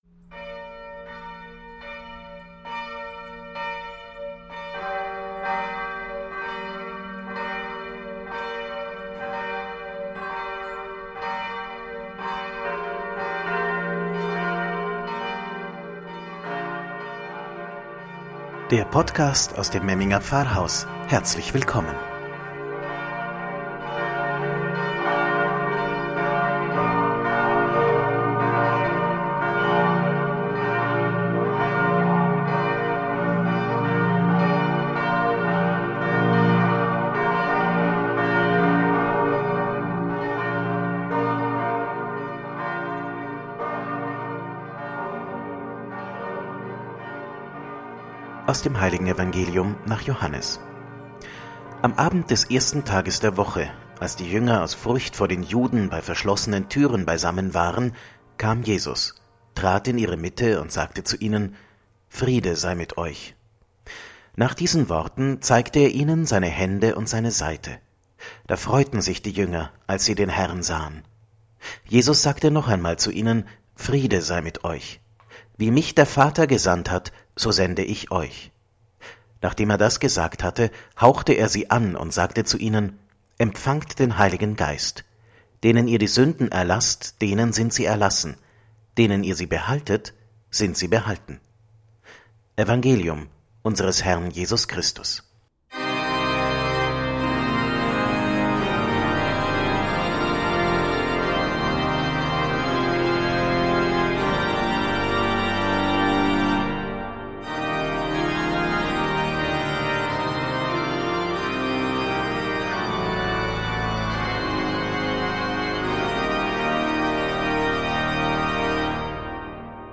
„Wort zum Sonntag“ aus dem Memminger Pfarrhaus – Pfingsten – Geistgeschenkt